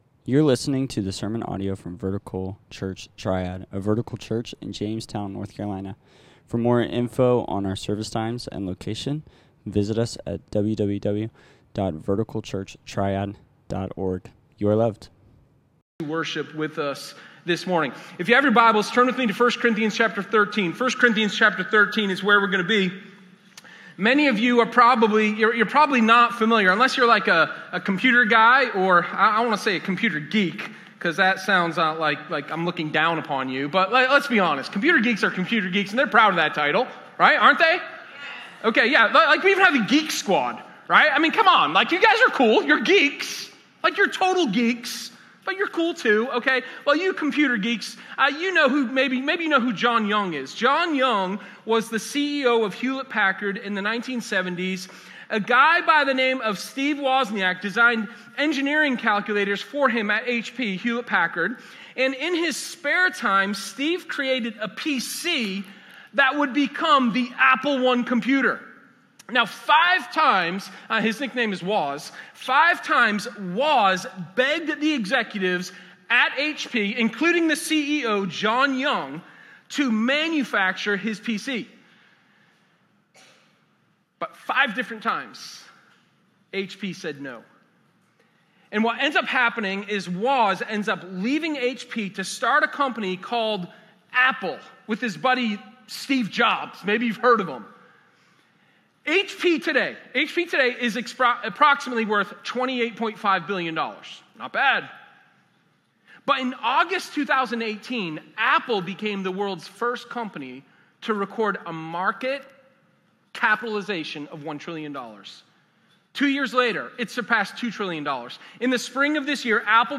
Sermon0911._One-Priority.m4a